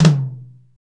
TOM P C HI1D.wav